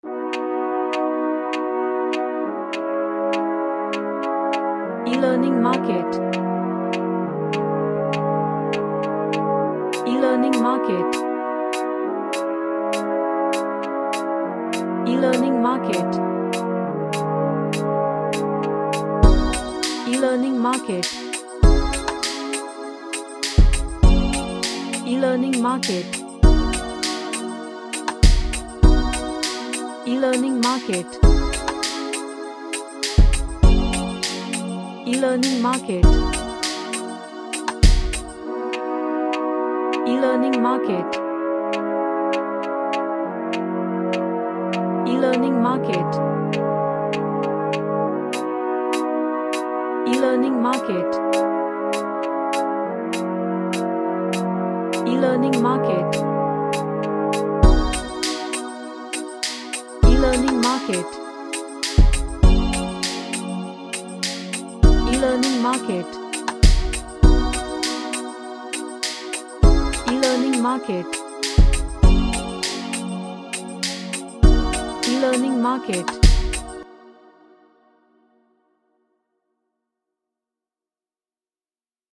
An Ambient track with lots of pads and melodical elements
Happy